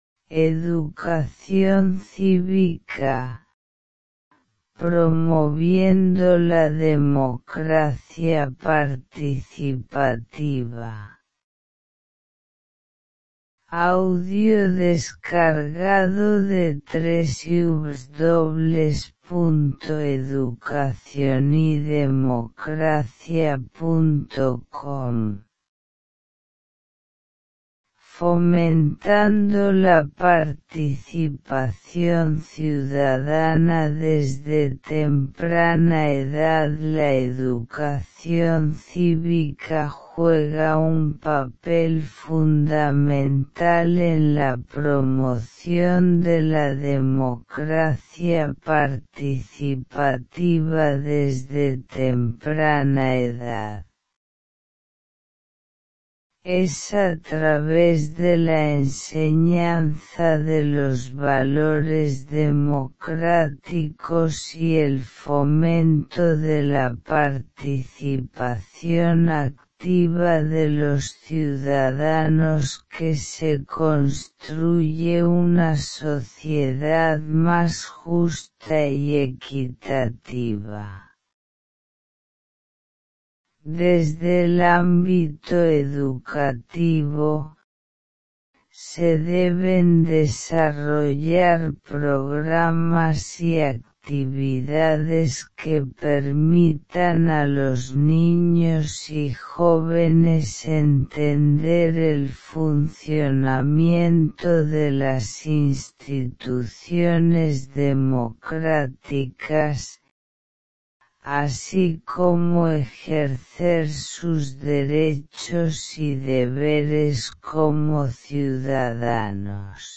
Descarga este artículo en formato de audio y sumérgete en el contenido sin complicaciones.